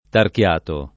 tarchiato [ tark L# to ] agg.